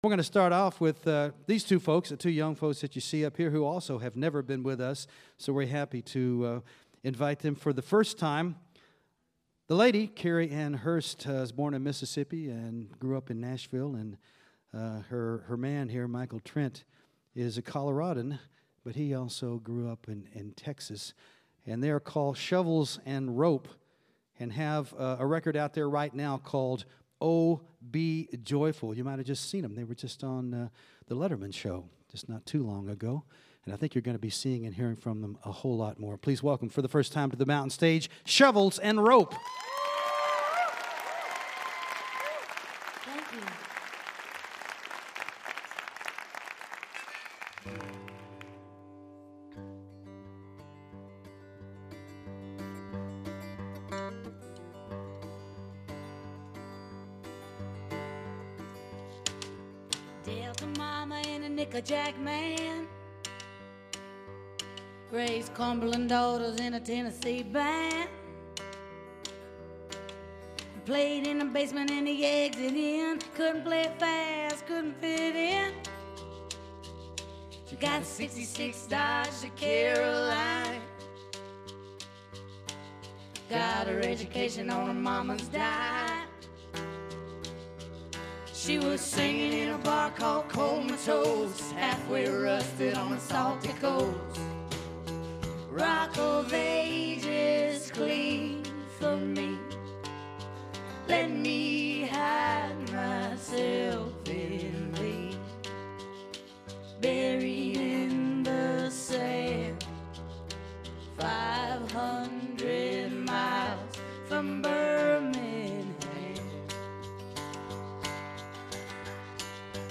the two play buoyant roots-rock songs from their debut album